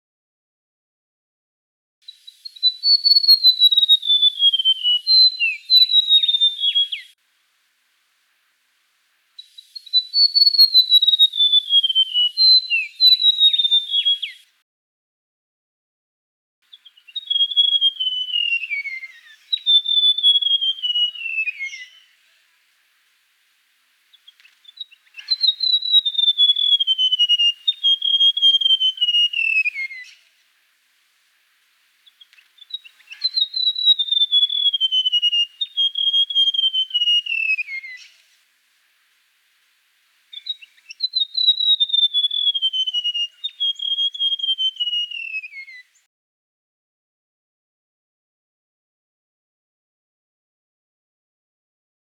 Unique Australian Bird Sounds
white throated gerygone
29-white-throated-gerygone.mp3